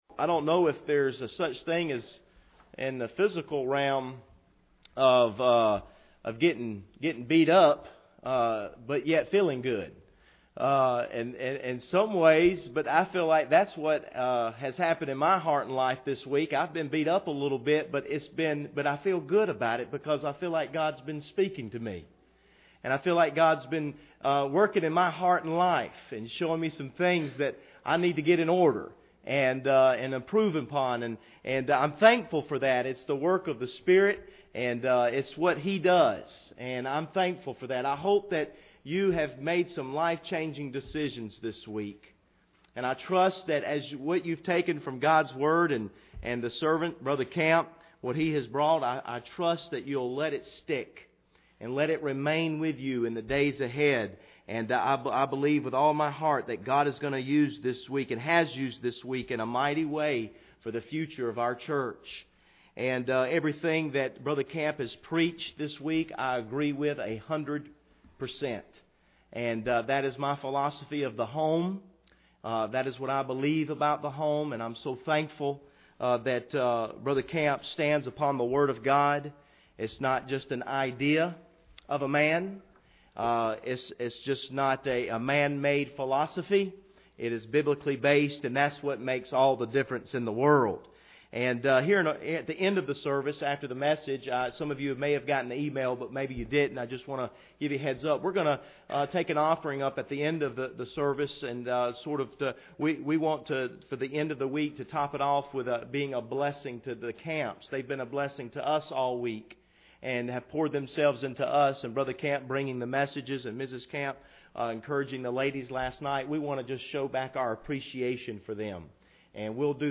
Series: 2017 Family Conference
Colossians 4:6 Service Type: Special Service Bible Text